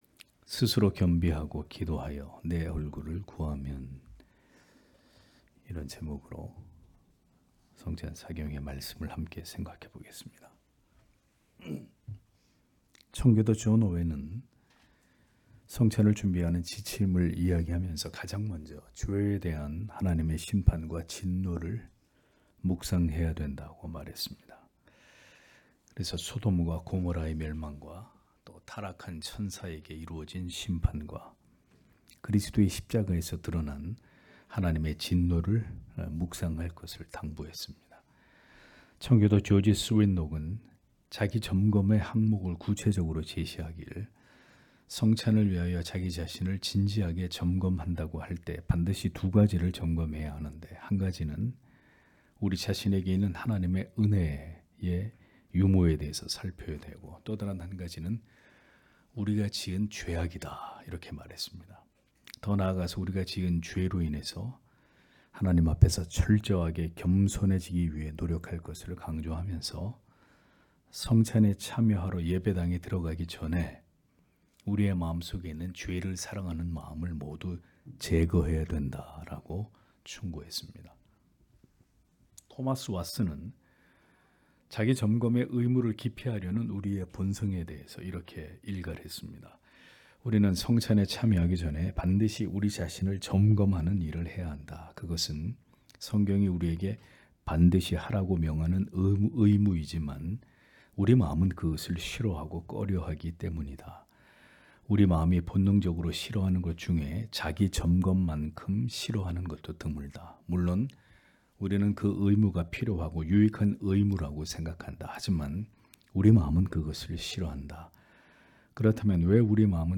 수요기도회 - [성찬 사경회 1] 스스로 겸비하고 기도하여 (대하 7장 13-14절)